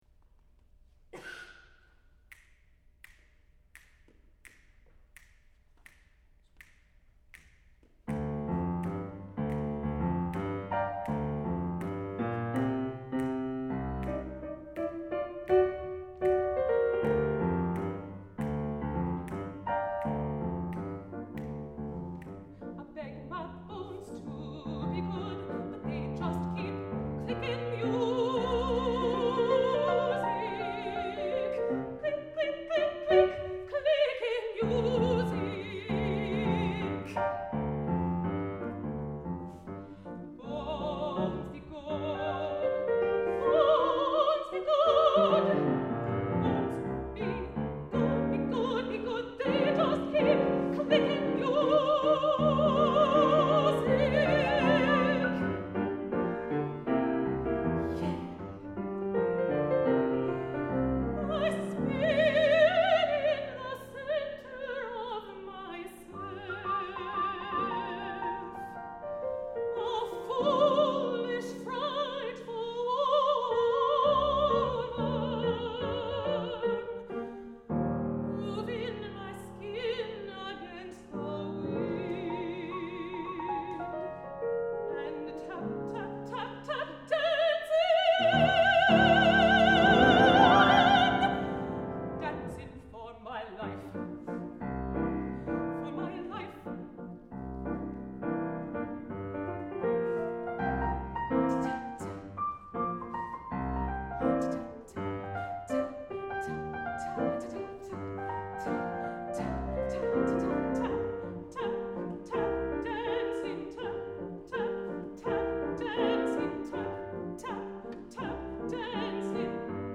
for Mezzo-soprano and Piano (1997)